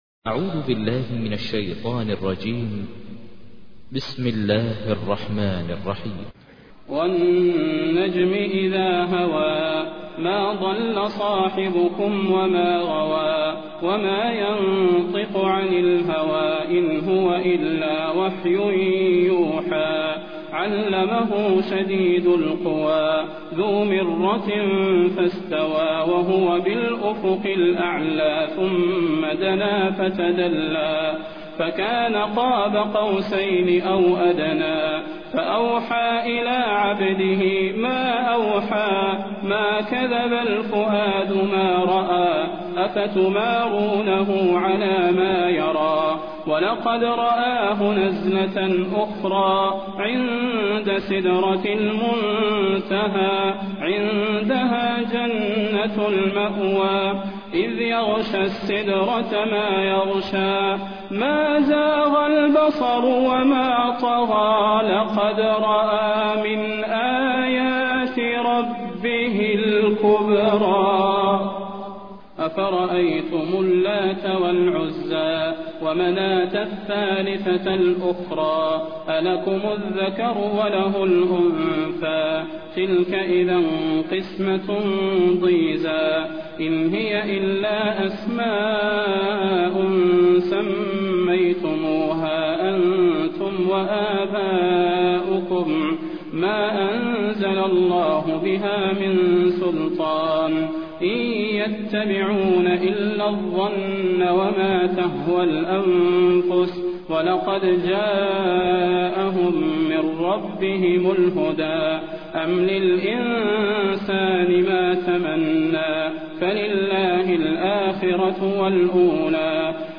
تحميل : 53. سورة النجم / القارئ ماهر المعيقلي / القرآن الكريم / موقع يا حسين